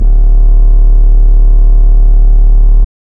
SGLBASS  1-R.wav